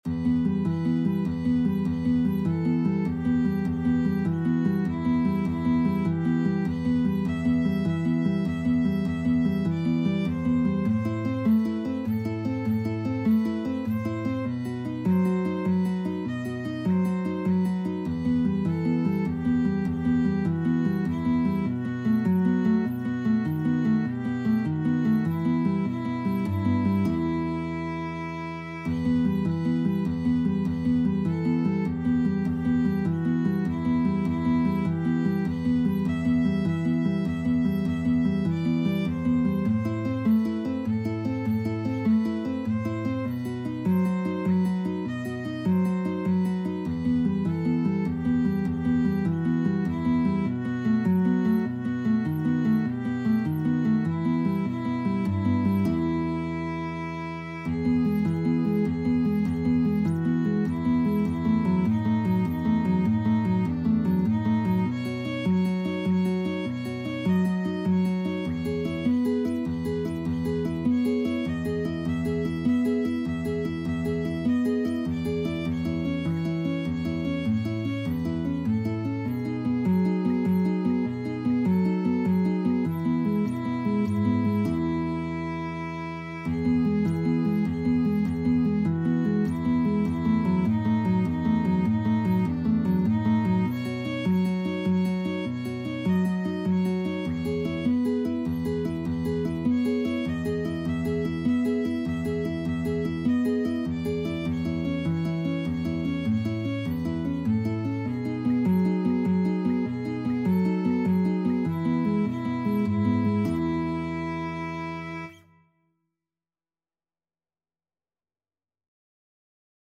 Free Sheet music for Violin-Guitar Duet
3/4 (View more 3/4 Music)
G major (Sounding Pitch) (View more G major Music for Violin-Guitar Duet )
Traditional (View more Traditional Violin-Guitar Duet Music)